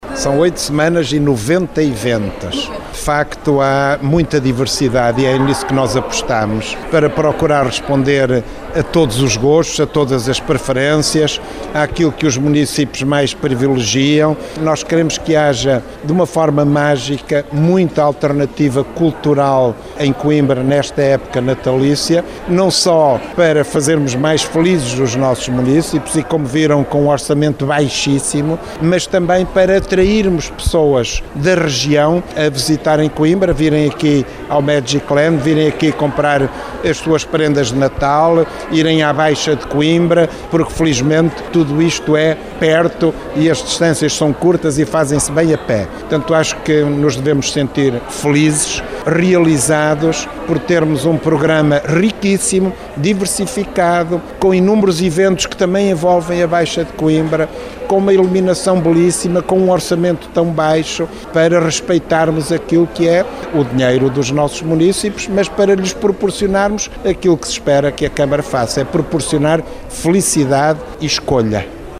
Ouça aqui José Manuel Silva, presidente da Câmara Municipal de Coimbra, na apresentação do Coimbra Natal’23: